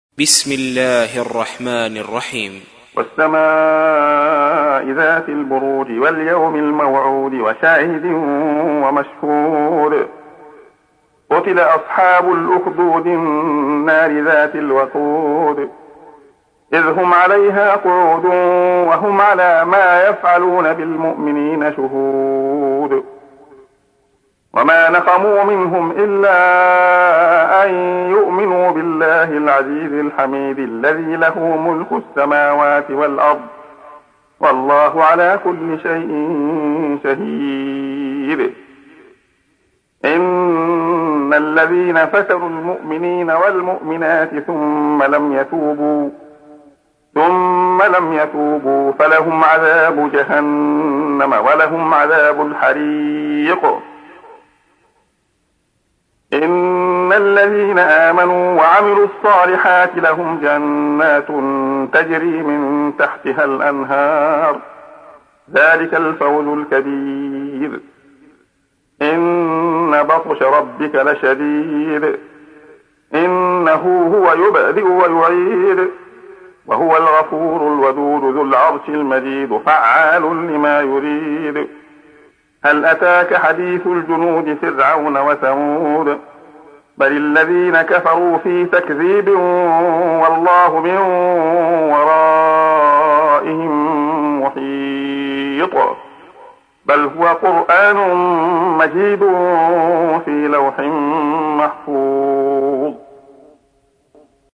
تحميل : 85. سورة البروج / القارئ عبد الله خياط / القرآن الكريم / موقع يا حسين